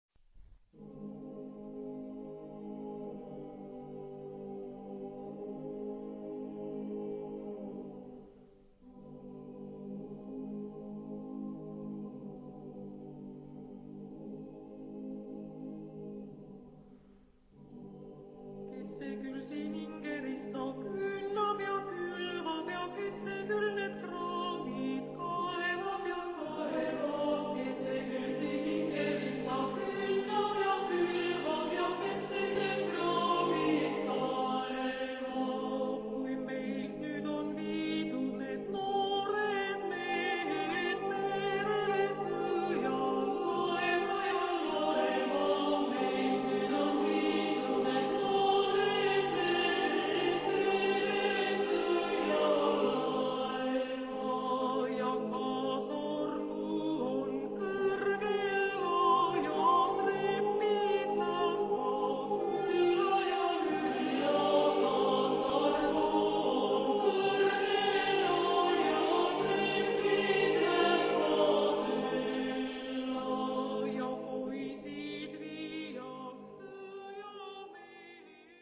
SATB (4 voices mixed).
Contemporary. Descriptive song. Secular.
Tonality: C minor